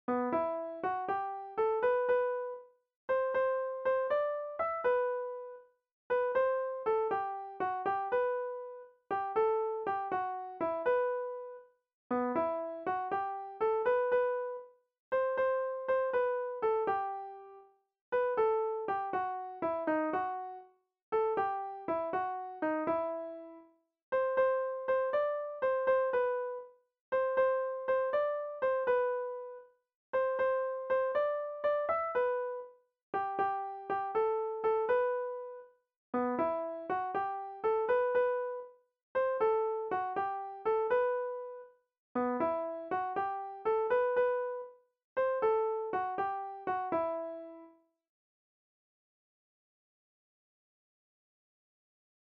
Kontakizunezkoa
A-B-C